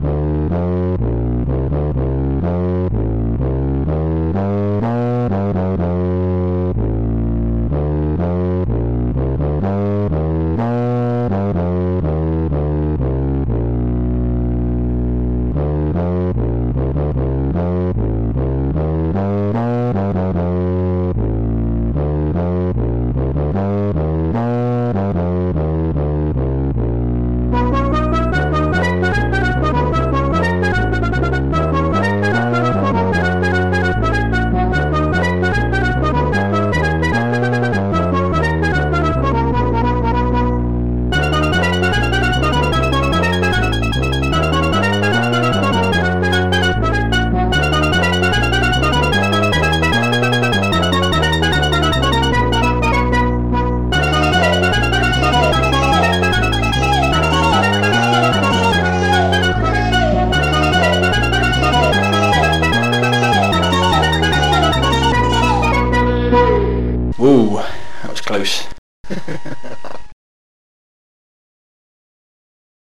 Protracker Module  |  1991-05-28  |  81KB  |  2 channels  |  44,100 sample rate  |  1 minute, 12 seconds
Protracker and family
Tuba.mp3